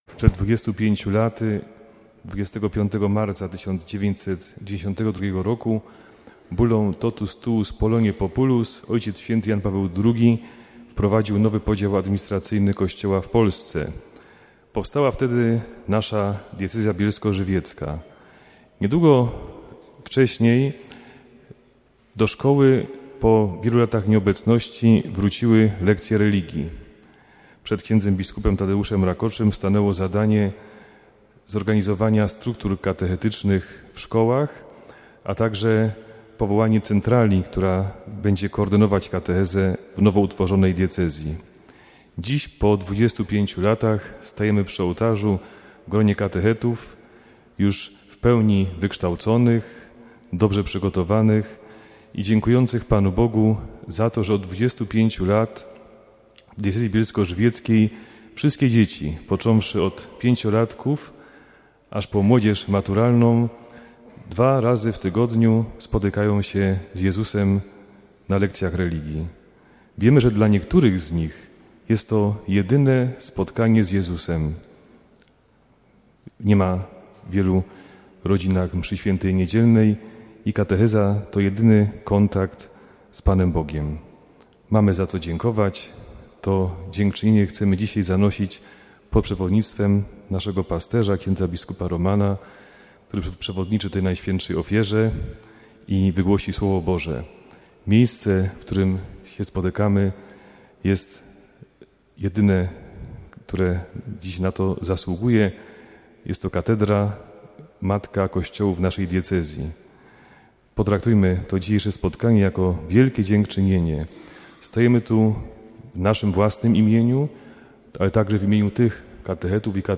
W katedrze w Bielsku-Białej modlili się 3 czerwca katecheci świeccy i zakonni z diecezji bielsko-żywieckiej, dziękując za 25 lat diecezji. Mszy św. przewodniczył biskup Roman Pindel, a po liturgii uczestnicy wysłuchali konferencji poświęconej duchowości błogosławionych ojców: Michała Tomaszka i Zbigniewa Strzałkowskiego.